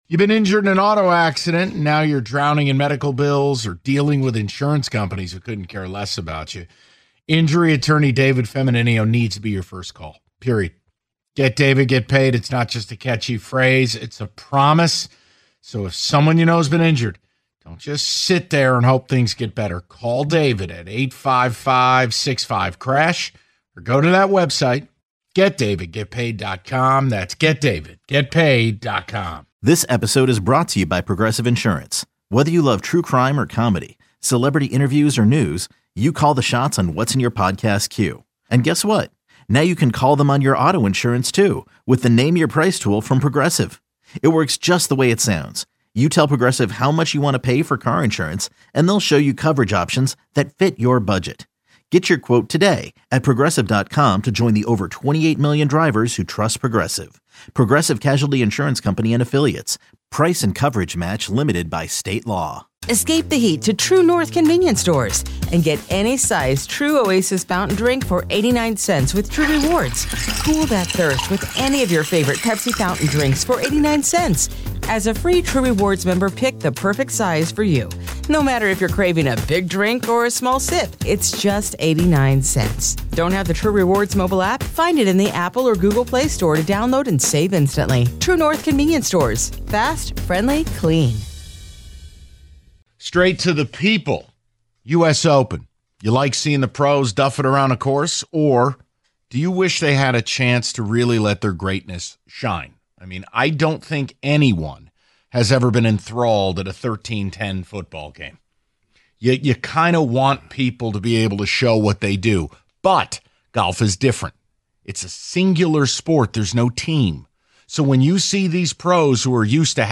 Taking Your Calls On Your Thoughts On The U.S. Open